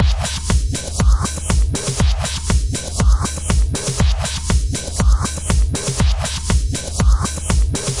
描述：铃鼓和一个轻型鼓来帮助打击乐。
标签： 120 bpm Cinematic Loops Percussion Loops 1.15 MB wav Key : Unknown
声道立体声